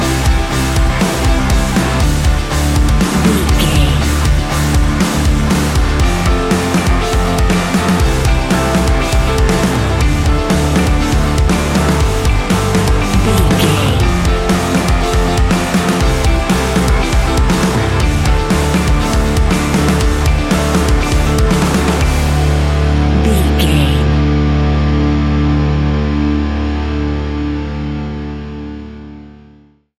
Ionian/Major
D♭
hard rock
heavy metal